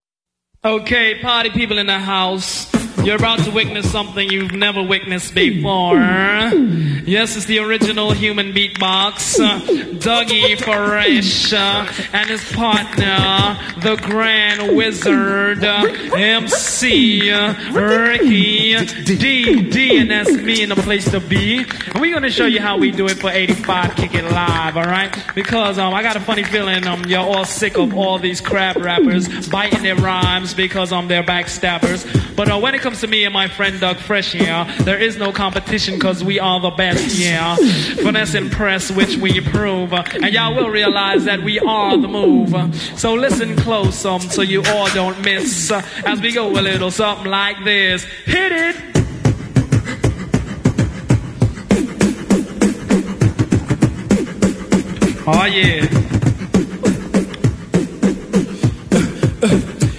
#80shiphop